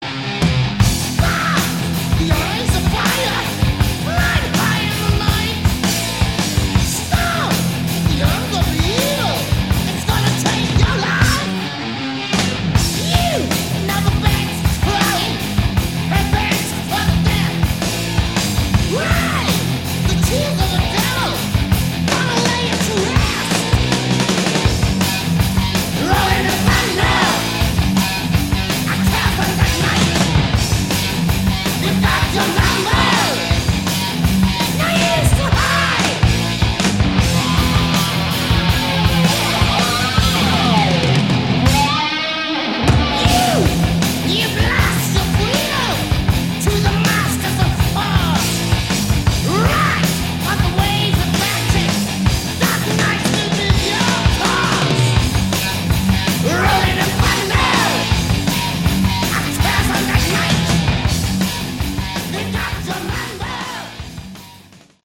Category: Metal